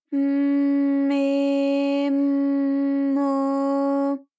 ALYS-DB-001-JPN - First Japanese UTAU vocal library of ALYS.
m_m_me_m_mo.wav